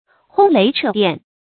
轟雷掣電 注音： ㄏㄨㄥ ㄌㄟˊ ㄔㄜˋ ㄉㄧㄢˋ 讀音讀法： 意思解釋： 雷聲隆隆，電光閃閃。